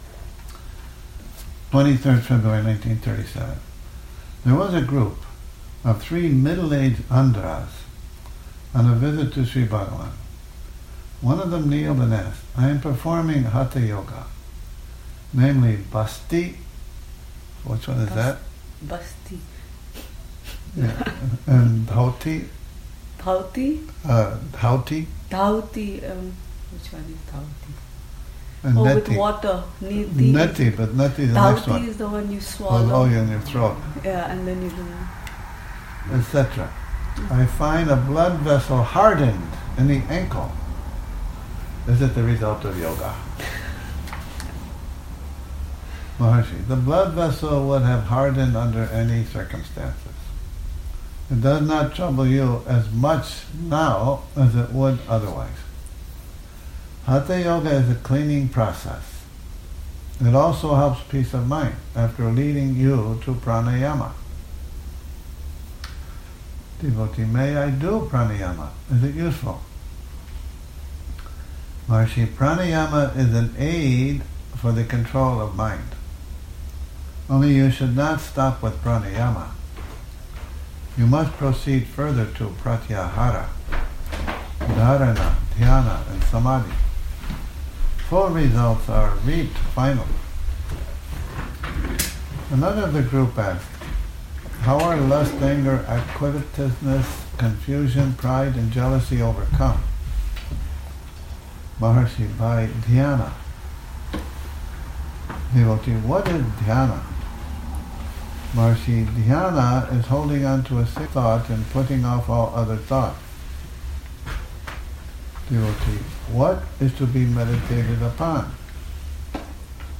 Morning Reading, 01 Oct 2019